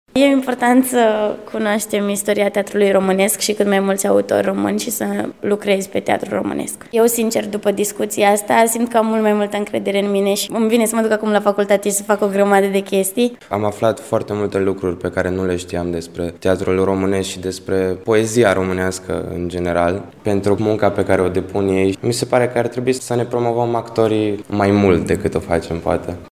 teatru-voxuri.mp3